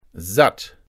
Begriffe von Hochdeutsch auf Platt und umgekehrt übersetzen, plattdeutsche Tonbeispiele, Schreibregeln und Suchfunktionen zu regelmäßigen und unregelmäßigen Verben.